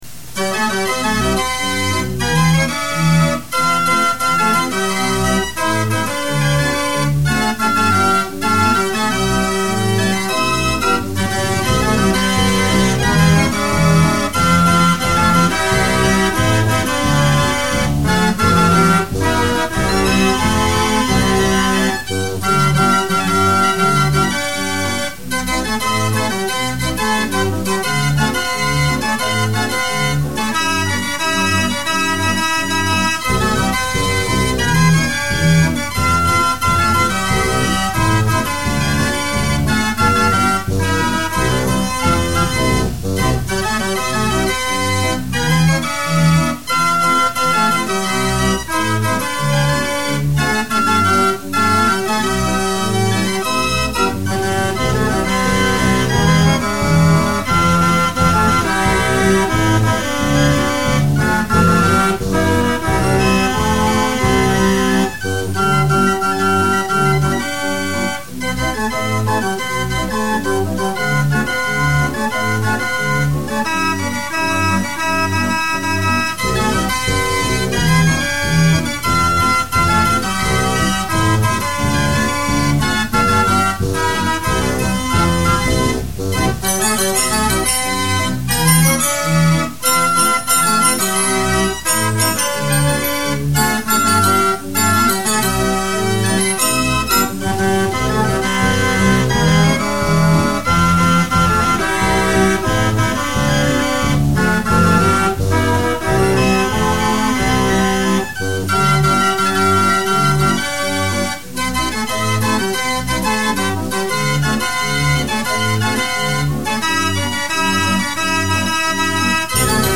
Fox Trot